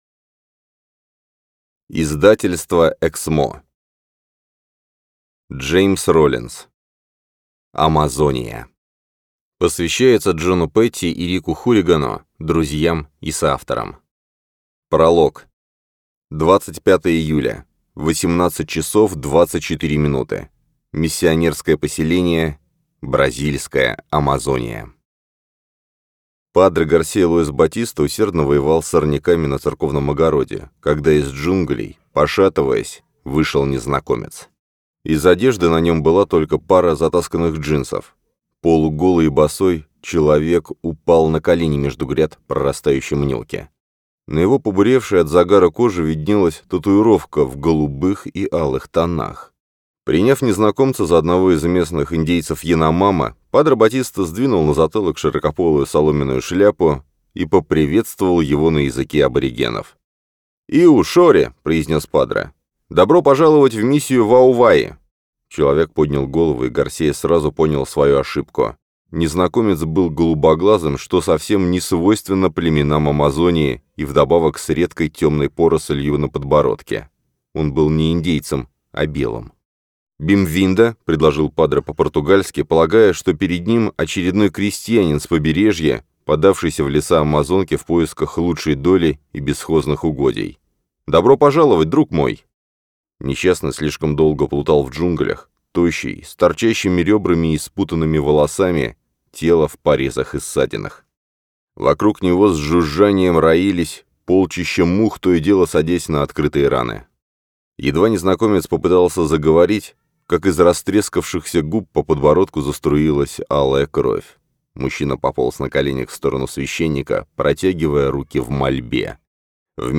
Аудиокнига Амазония | Библиотека аудиокниг